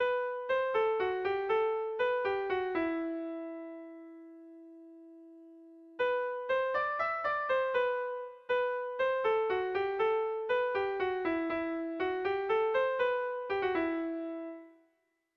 Air de bertsos - Voir fiche   Pour savoir plus sur cette section
Kontakizunezkoa
A1A2